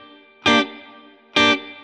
DD_StratChop_130-Gmin.wav